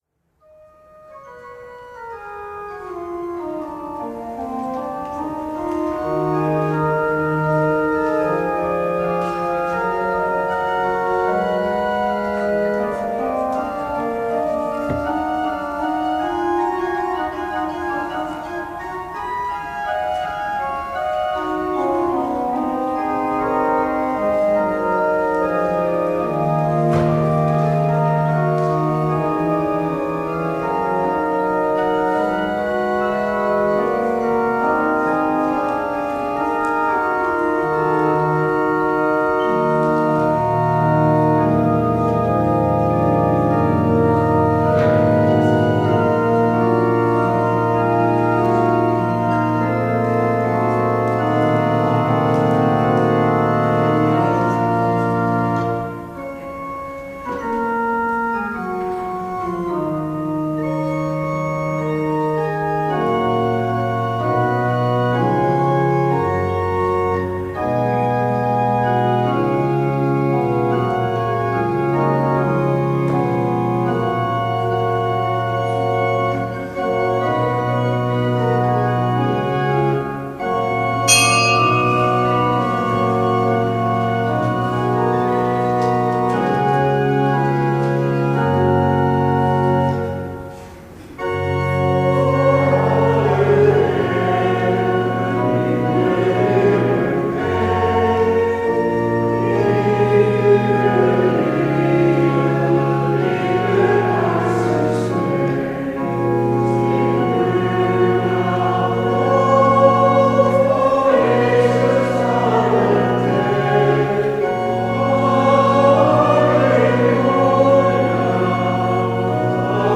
Eucharistieviering beluisteren vanuit de St. Jozefkerk te Wassenaar (MP3)